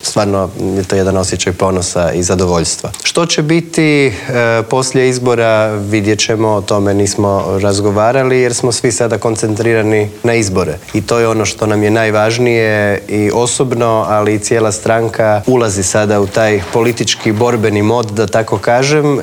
ZAGREB - Dan nakon raspuštanja 10. saziva Sabora i uoči odluke predsjednika Zorana Milanovića da će se parlamentarni izbori održati u srijedu 17. travnja, u Intervjuu tjedna Media servisa gostovao je predsjednik Sabora Gordan Jandroković.